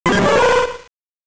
サウンド素材「ポケモン鳴き声」